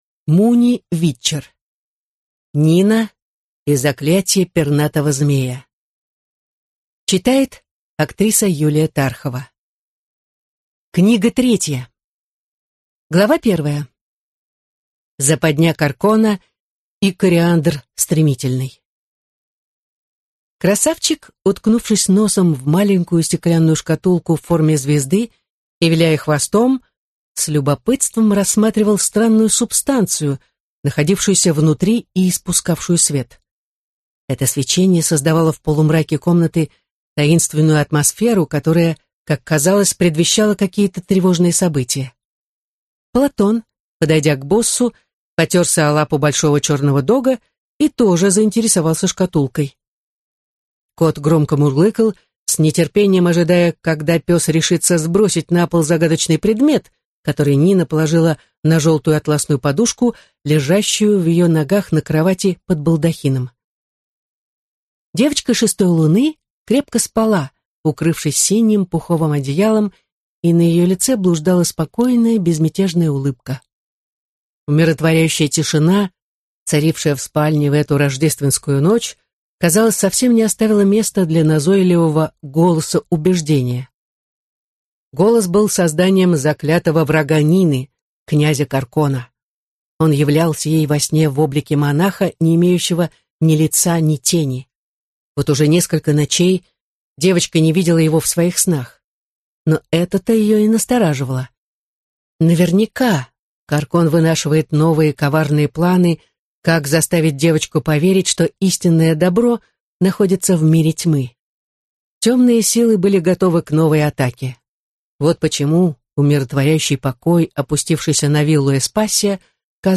Аудиокнига Нина и заклятье Пернатого Змея | Библиотека аудиокниг